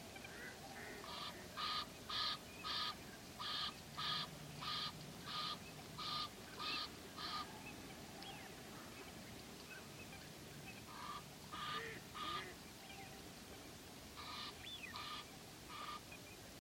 ouette-d-egypte.mp3